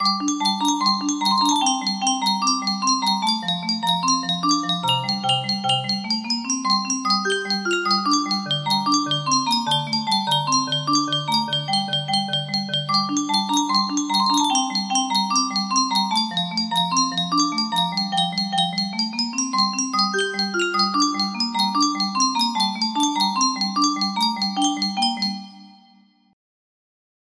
korobeiniki music box melody
Full range 60